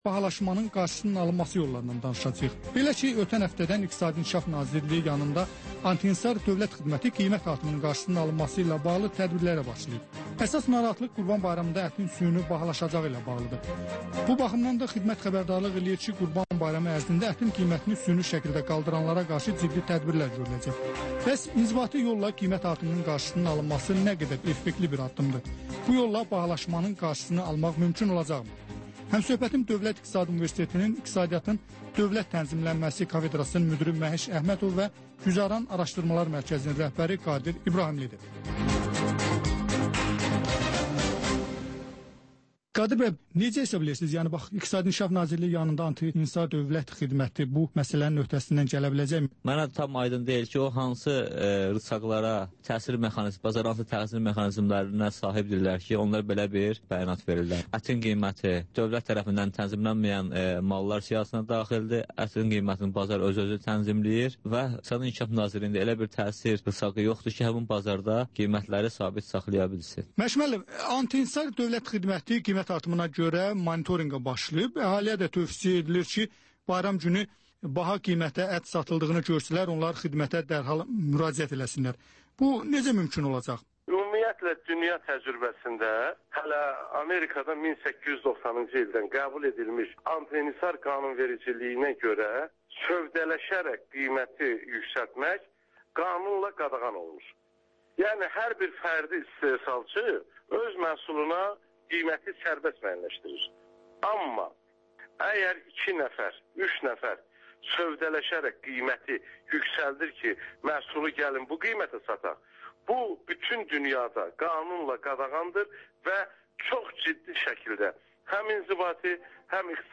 Korrupsiya haqqında xüsusi veriliş.